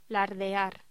Locución: Lardear
voz